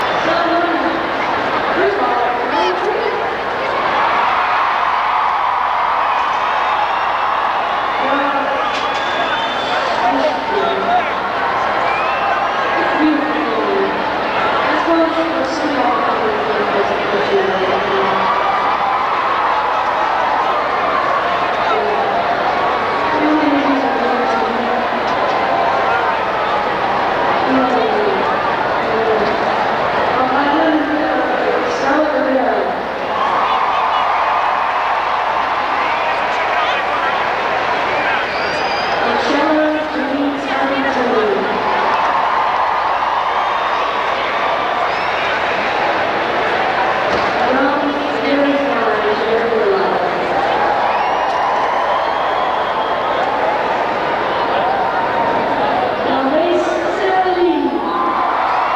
10. band introductions (0:58)